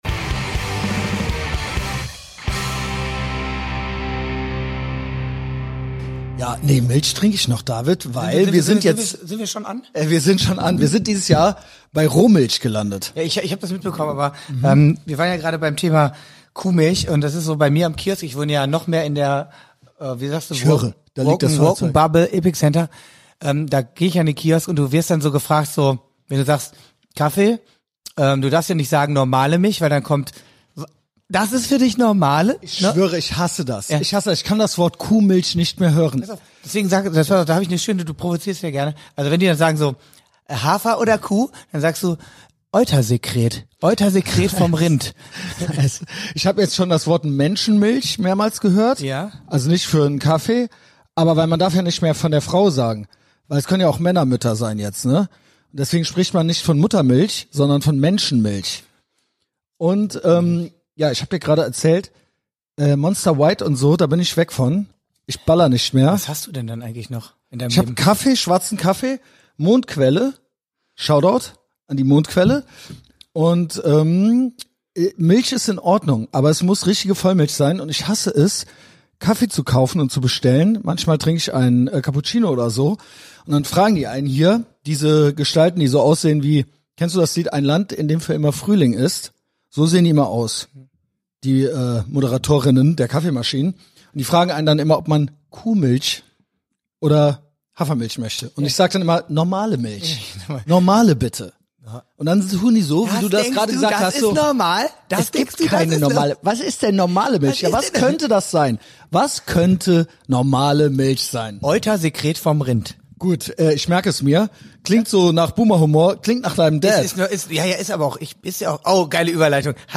Aufgenommen at the compound in Ehrenfeld.